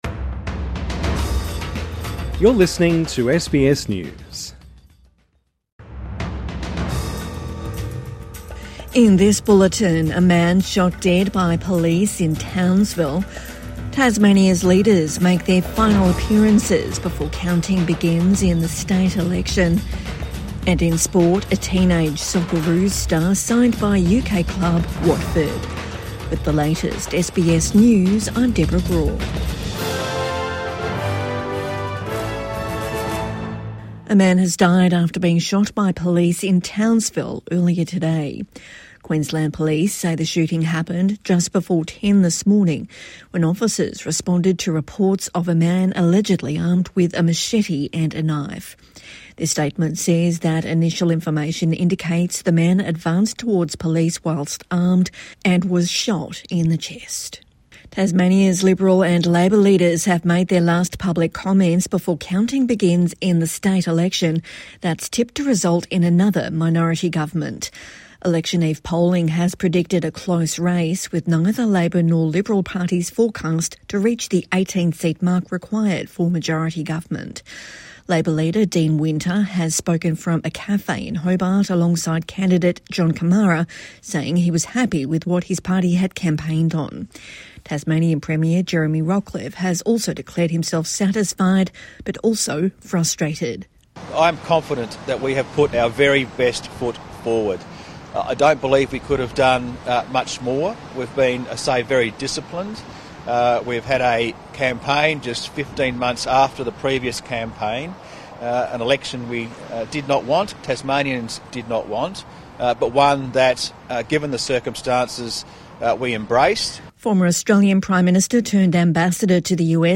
Evening News Bulletin 19 July 2025